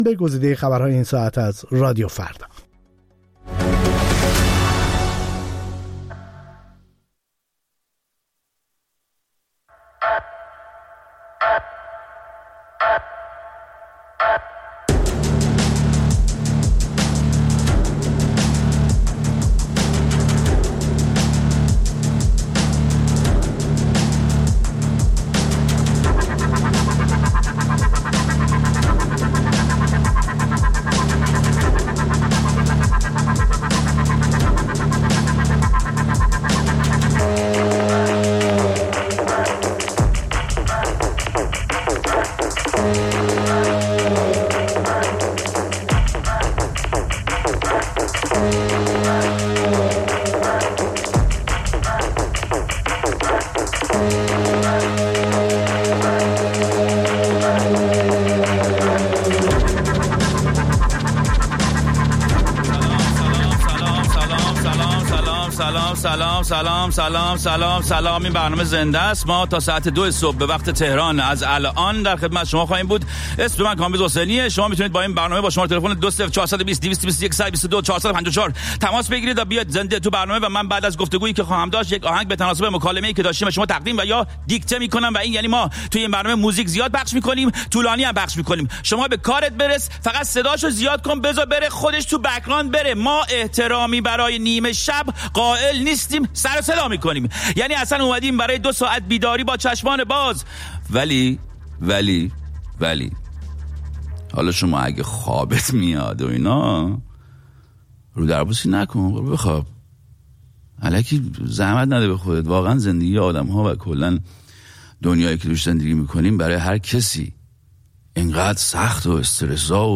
پارادوکس؛ زنده از لس‌آنجلس